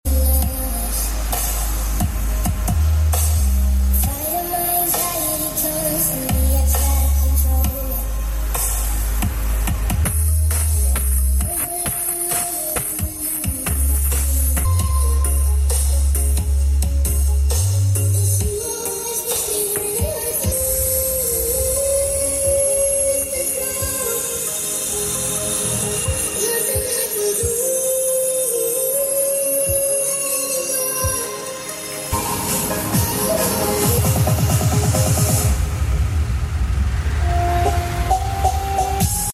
SOUNDTEST SPEAKER AKTIF POLYTRON 1SET. sound effects free download